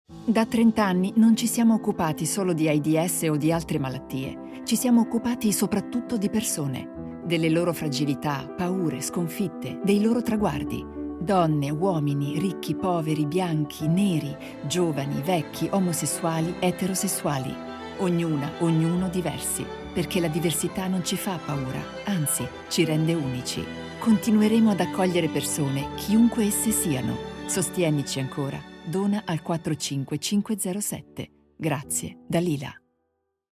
Profi-Sprecherin Italienisch Muttersprache
Sprechprobe: Werbung (Muttersprache):
female voice over talent italian mother tongue